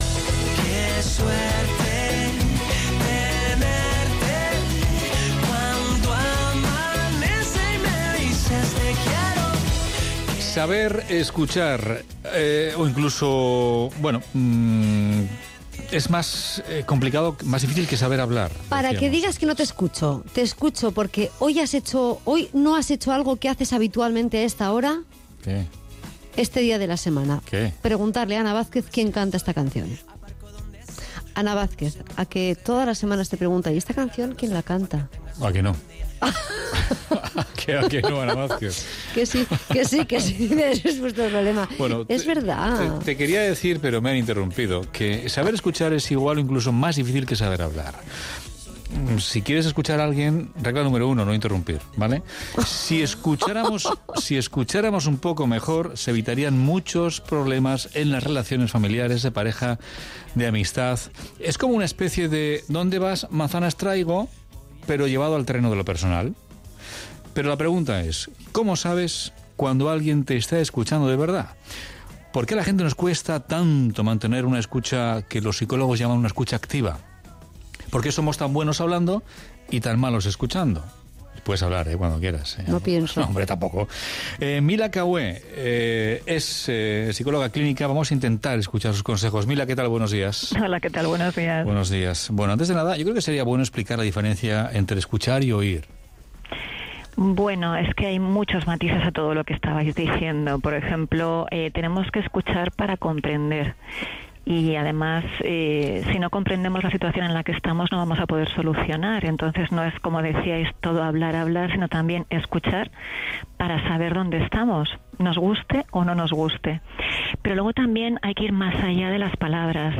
Y también hay que saber mirar, y saber sentir. Agradabilísima conversación